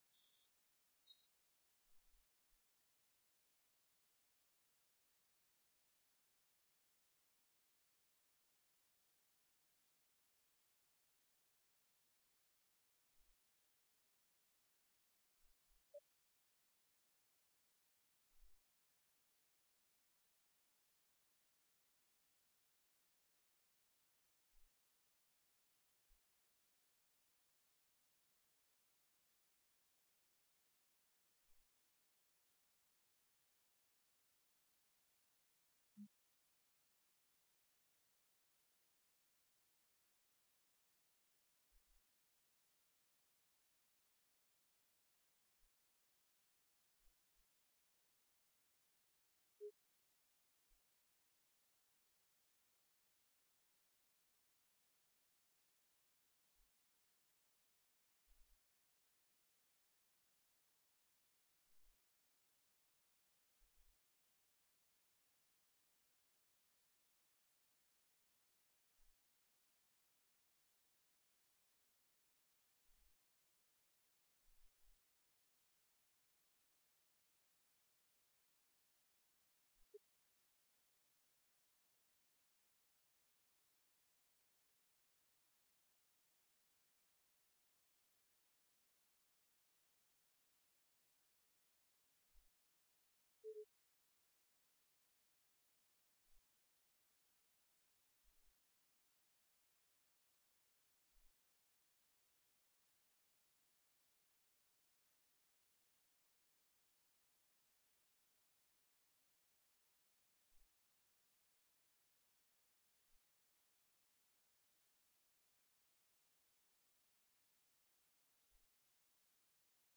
تاريخ النشر ١٠ ذو الحجة ١٤٣٦ هـ المكان: المسجد النبوي الشيخ: فضيلة الشيخ عبدالباري الثبيتي فضيلة الشيخ عبدالباري الثبيتي كمال الدين الإسلامي